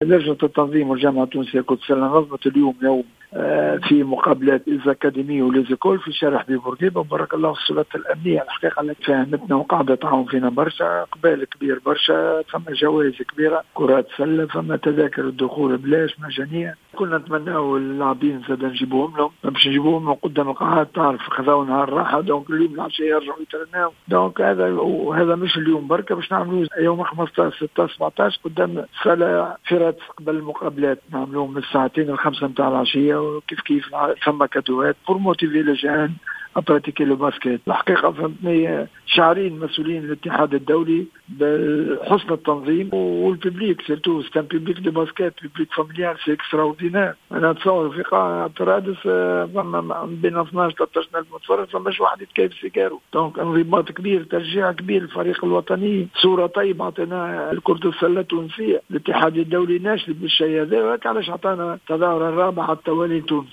تصريح لجوهرة أف ام